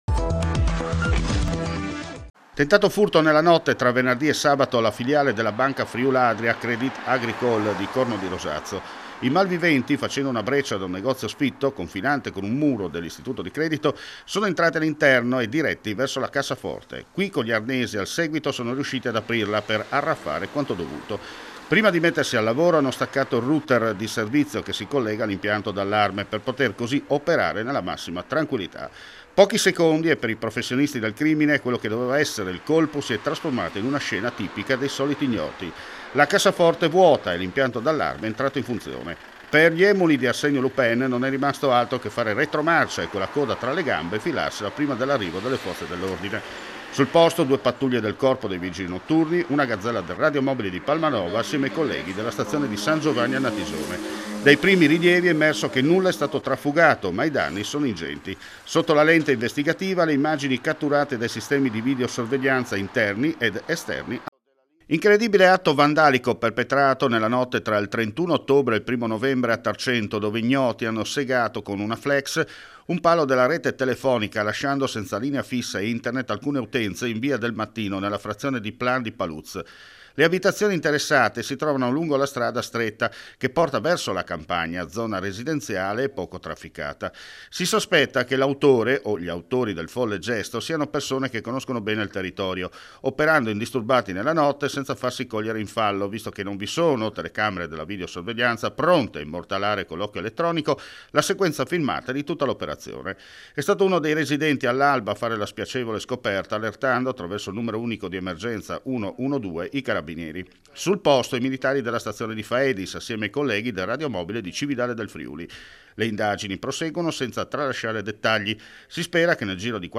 FRIULITV GIORNALE RADIO: LE ULTIME AUDIONOTIZIE DEL FRIULI VENEZIA GIULIA – FriuliTv Networking